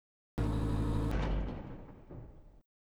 added more sfx
door.wav